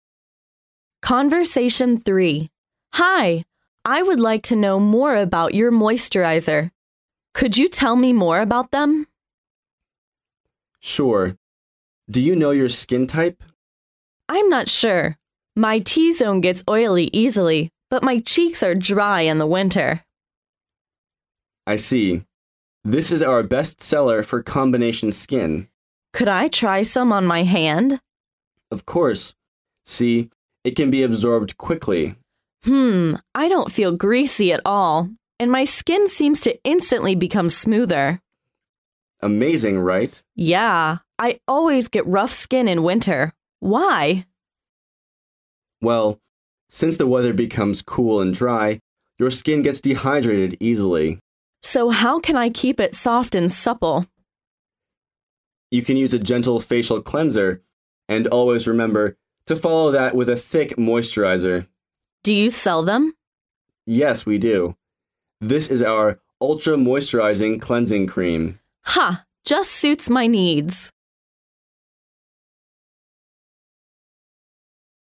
Conversation 3